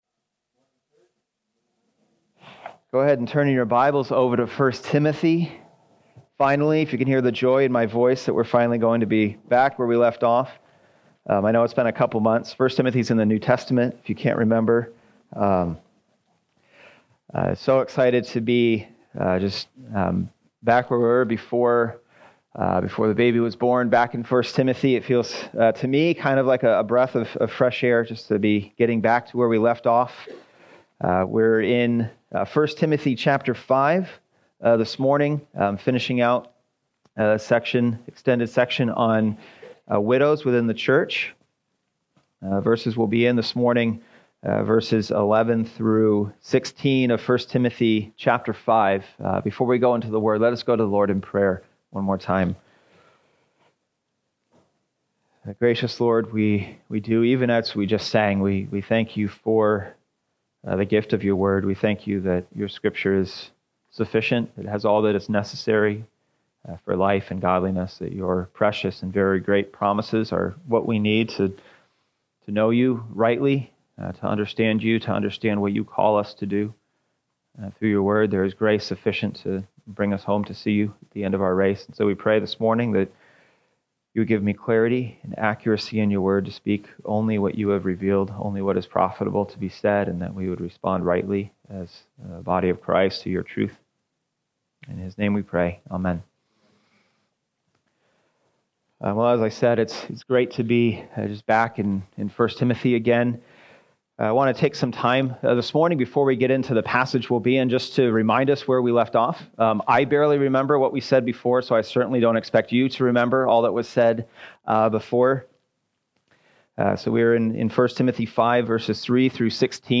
Sunday Morning - Fellowship Bible Church